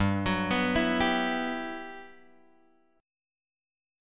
G Major 13th
GM13 chord {3 2 5 2 5 2} chord